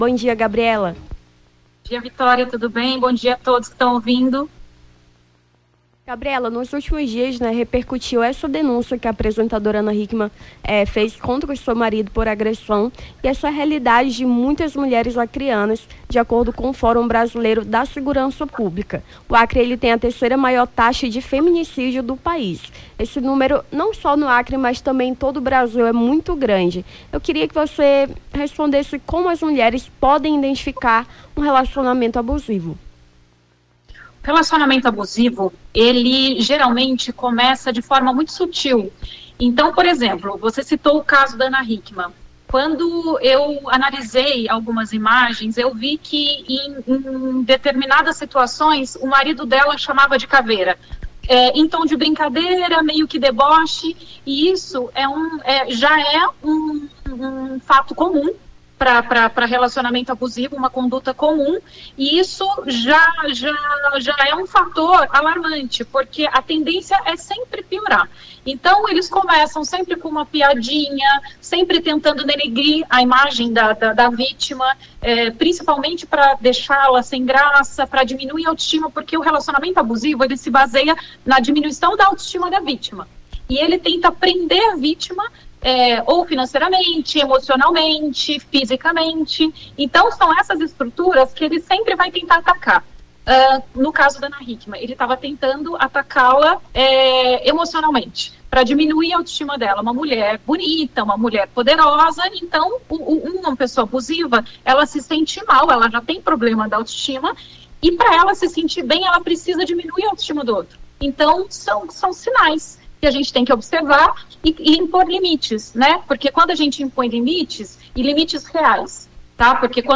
Nome do Artista - CENSURA - ENTREVISTA (CASO ANA HICKMANN) 15-11-23.mp3